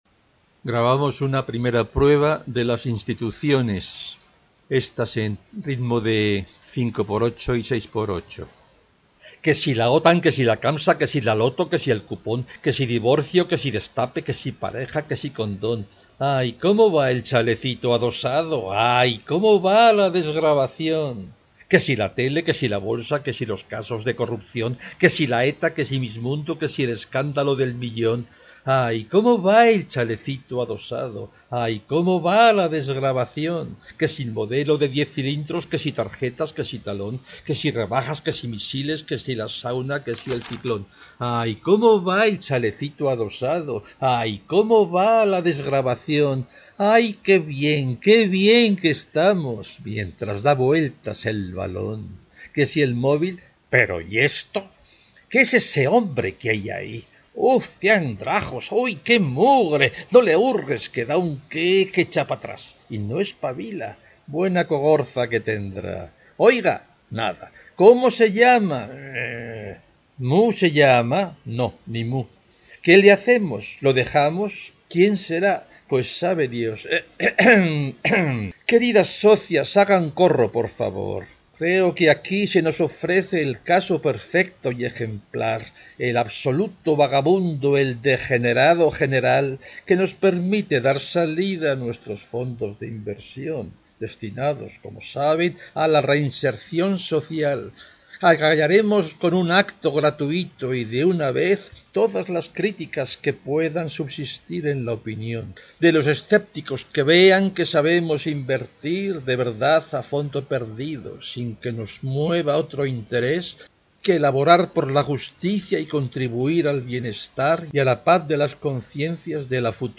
3.1 Que si la Otan...'   5/27 melopeya 5/8, 6/8       1.